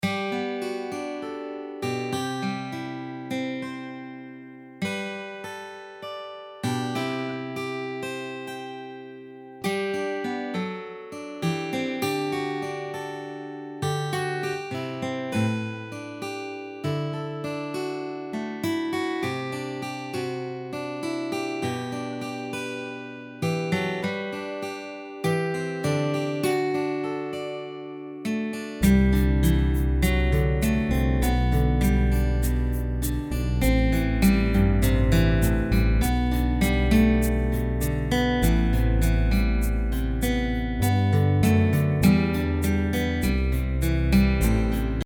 Instrumental mp3 Track